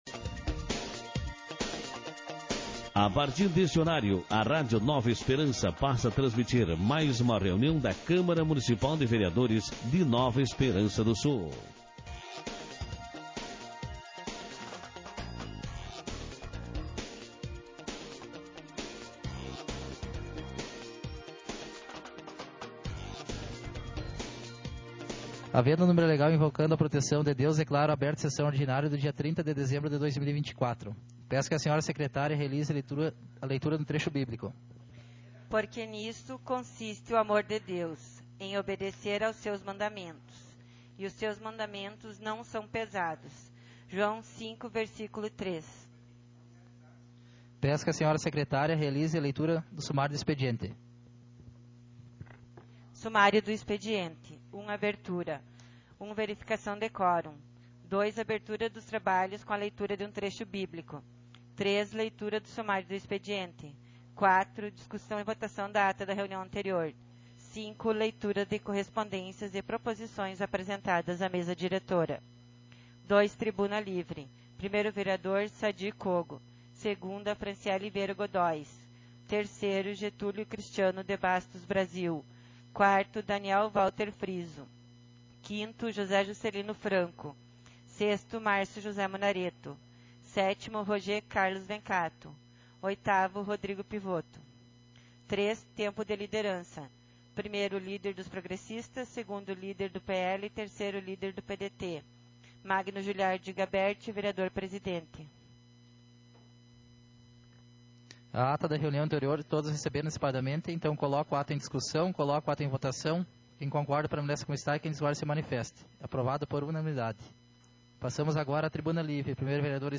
audio da sessao ordinária do dia 30-12-24
sessão ordinária